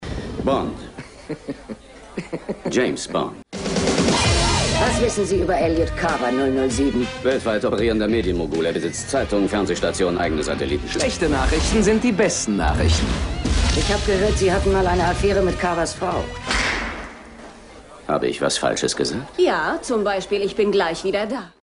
Frank Glaubrecht ~ Synchronsprecher
Ob in markant-seriösem oder männlich-sinnlichem Ton, Frank Glaubrecht leiht sie den größten Stars des Hollywoodfilms.
Frank_Glaubrecht_Pierce_Brosnan.mp3